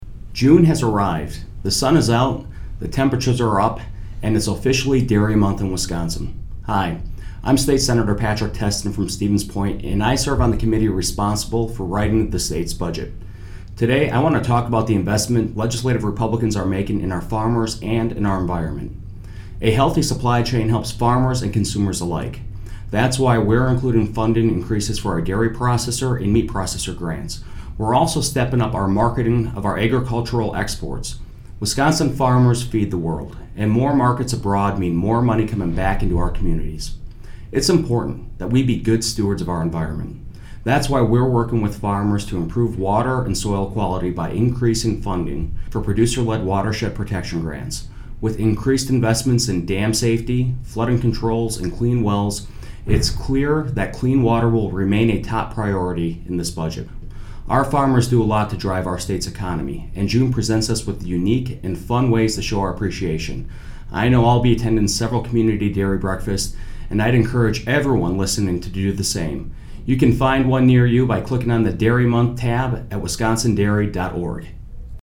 Weekly GOP radio address: Sen. Testin on investing in farmers and our environment - WisPolitics